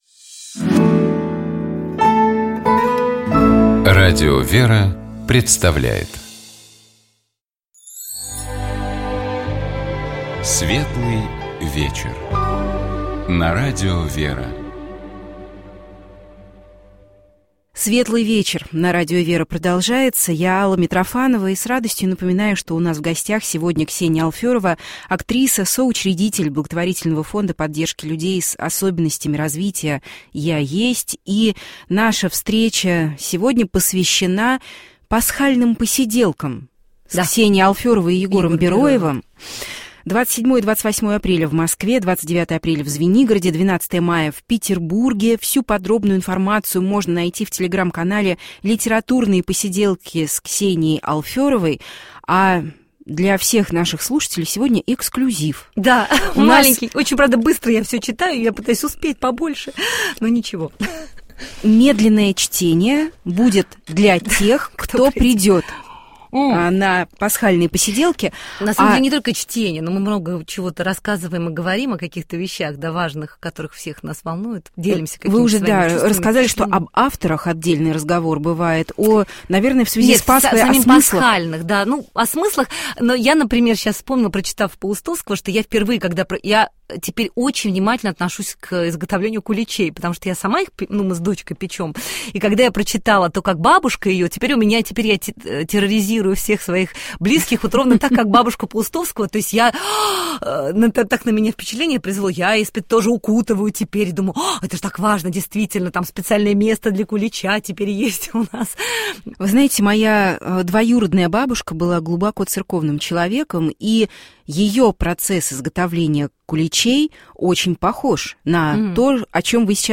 У нас в гостях была актриса, соучредитель фонда поддержки людей с особенностями развития «Я есть» Ксения Алферова.